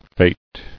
[fete]